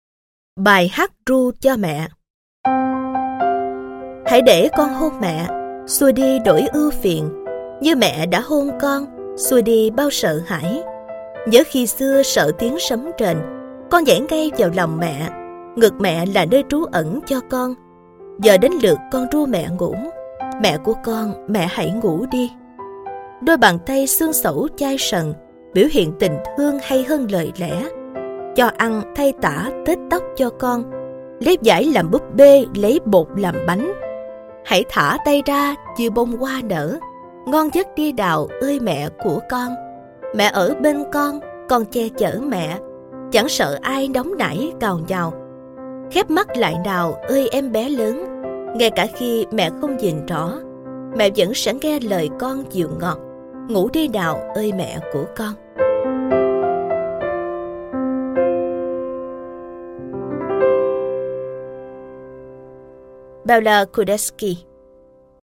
Sách nói Chicken Soup 9 - Vòng Tay Của Mẹ - Jack Canfield - Sách Nói Online Hay